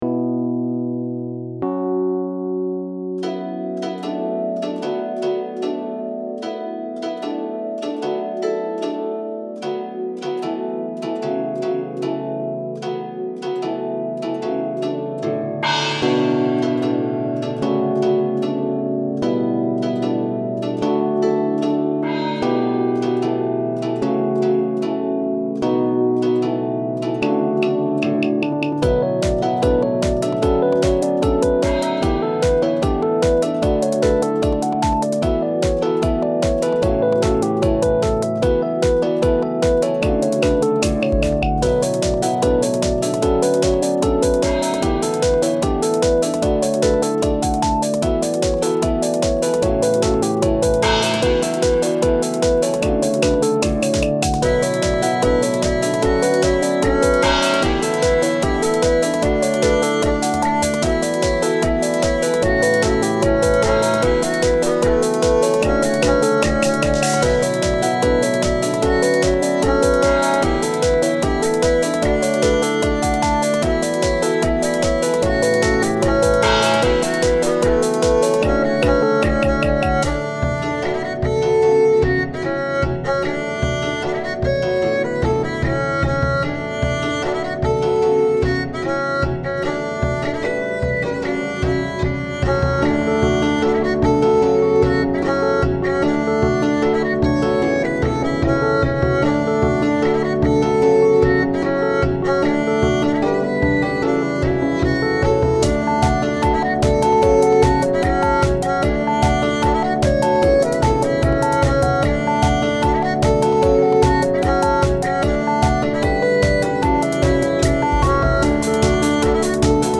BGM
ロング民族穏やか